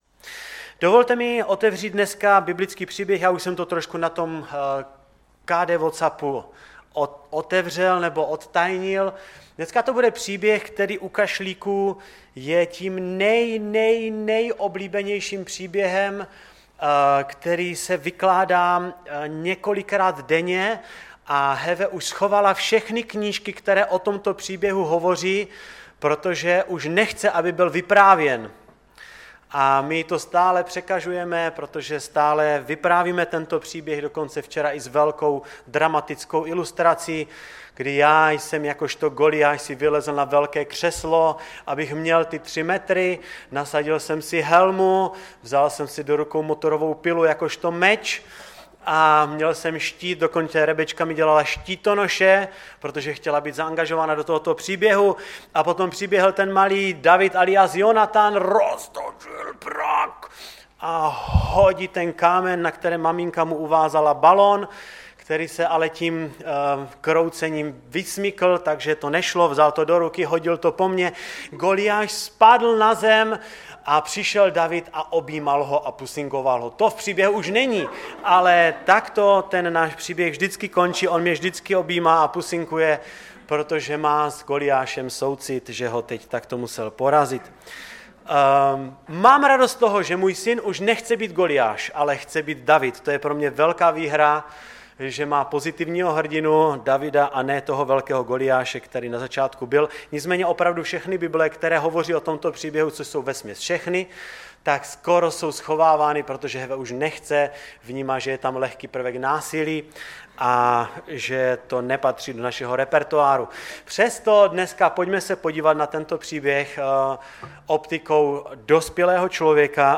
ve sboře Ostrava-Radvanice.
Kázání